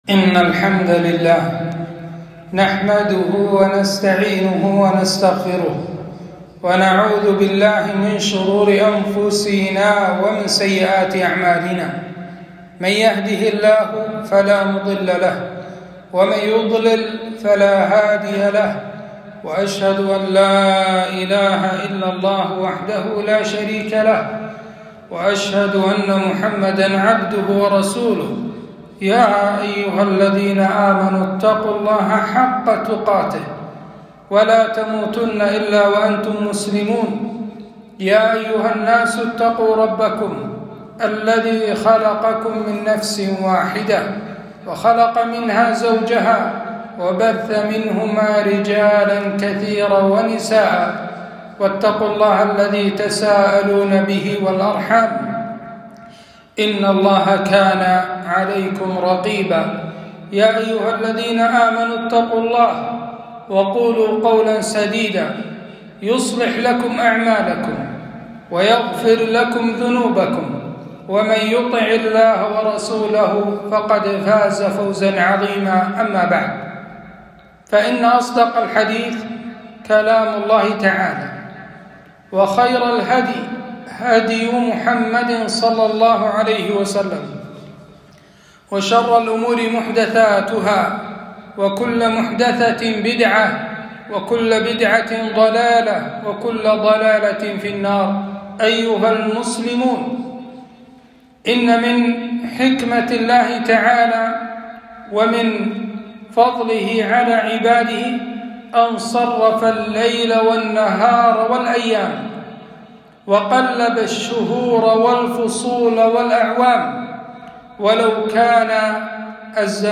خطبة - أحكام الشتاء